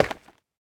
Minecraft Version Minecraft Version latest Latest Release | Latest Snapshot latest / assets / minecraft / sounds / block / basalt / step1.ogg Compare With Compare With Latest Release | Latest Snapshot
step1.ogg